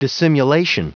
Prononciation du mot dissimulation en anglais (fichier audio)
Prononciation du mot : dissimulation